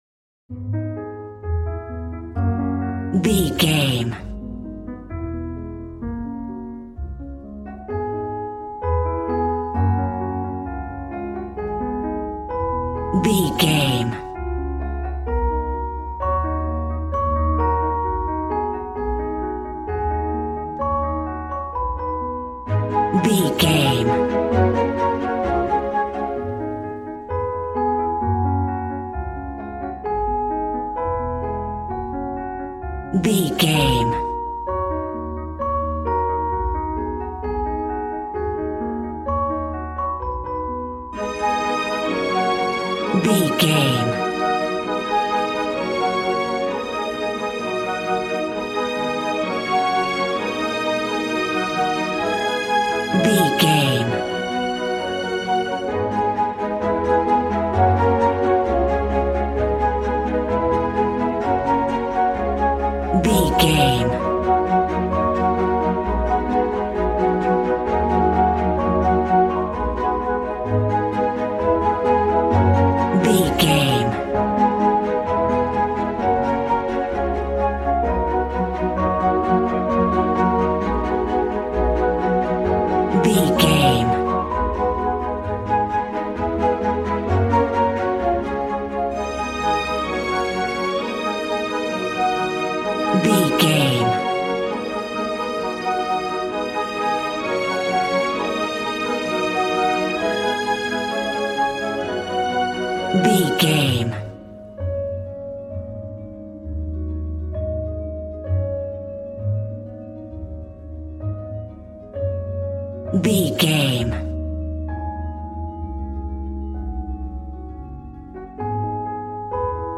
Regal and romantic, a classy piece of classical music.
Aeolian/Minor
A♭
regal
strings
violin